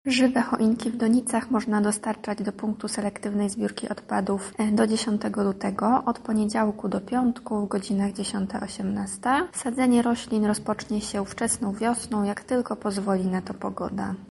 • mówi